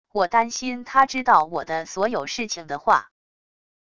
我担心她知道我的所有事情的话wav音频生成系统WAV Audio Player